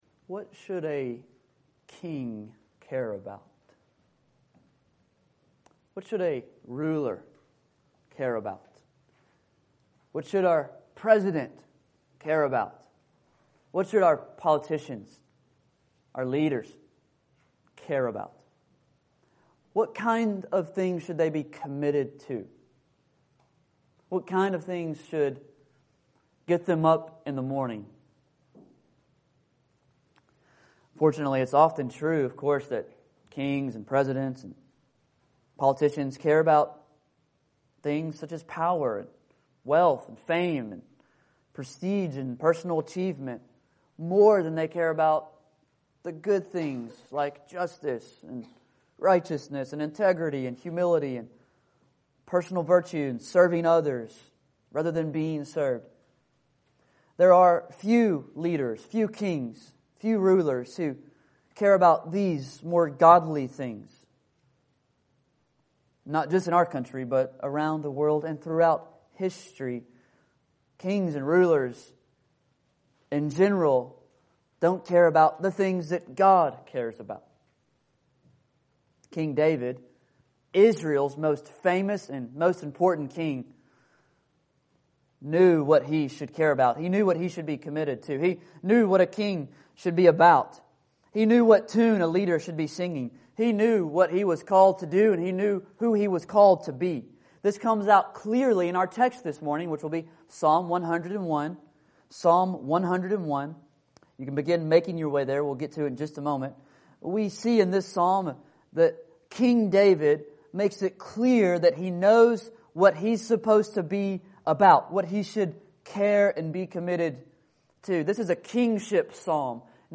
If you’d like me to preach a sermon for you when you move, just let me know!